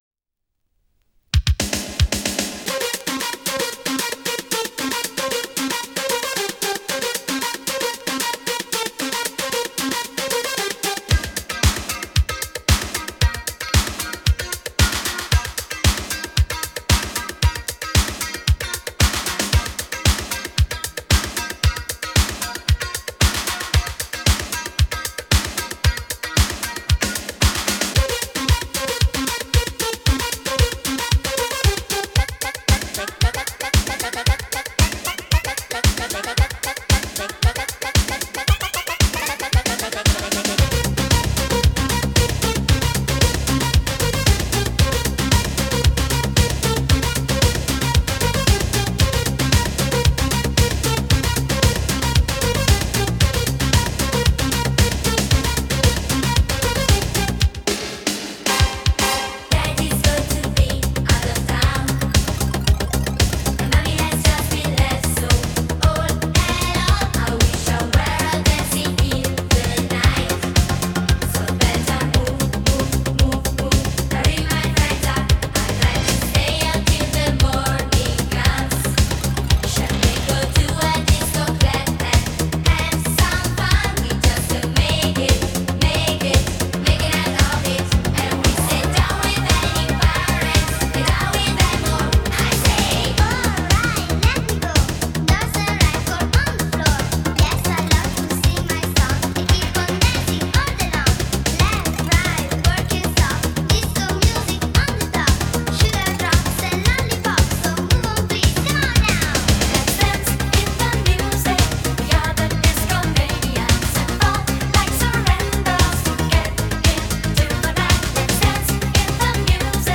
Pop, Italo-Disco